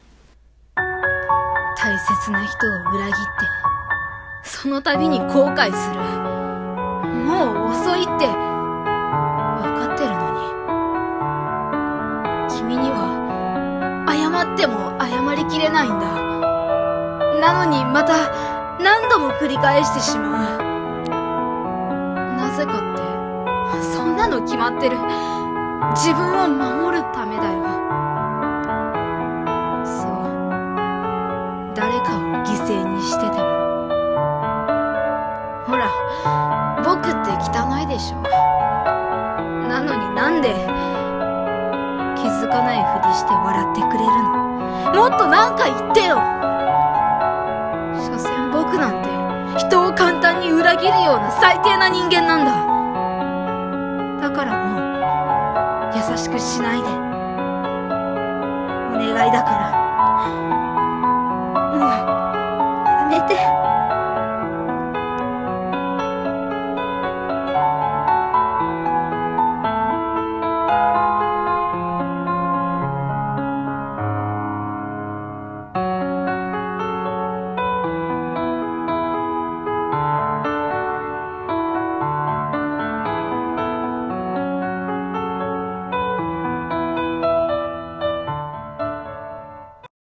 【1人声劇台本】